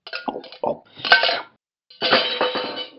Talking Ben Eat Sound Effect Free Download